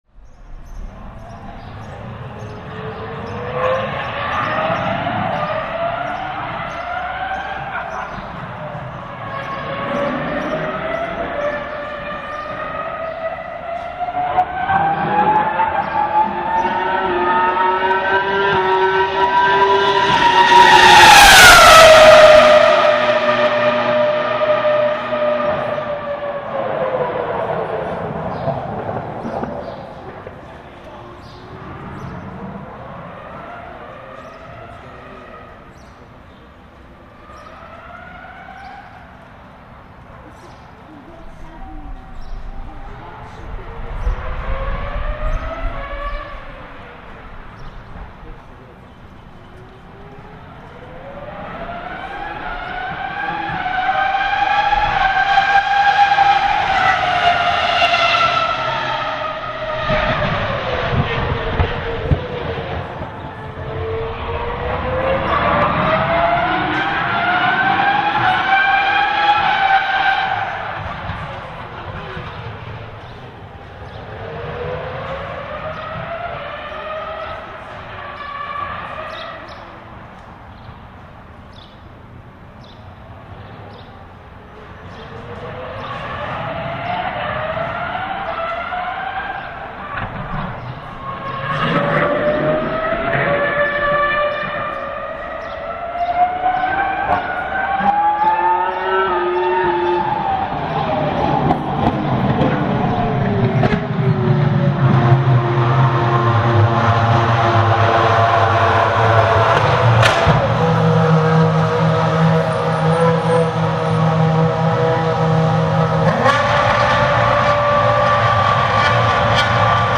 Williams BMW FW25 V10 Sound
Hear the Williams BMW V10 (FW25) with Ralf Schumacher doing a test drive (2003) on the Estoril circuit near Lissabon in Portugal.
bmw.williamsf1_Estoril_ralf_sch.mp3